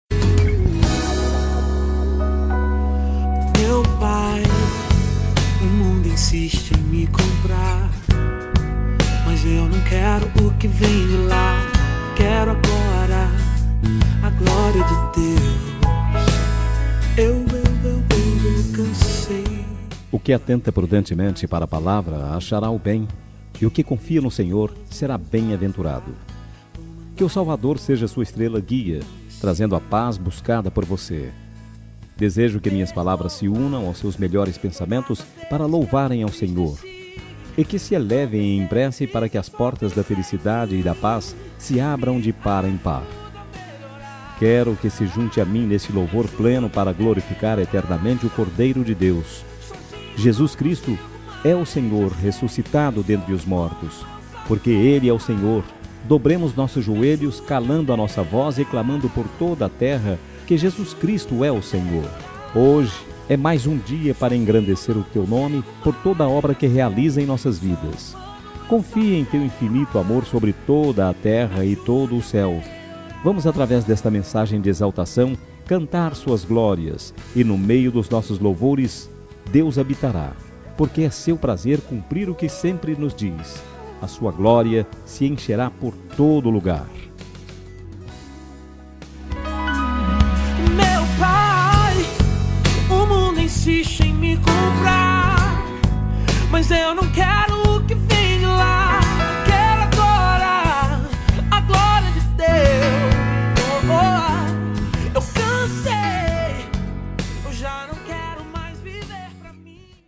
NEUTRA EVANGÉLICA
Voz Masculina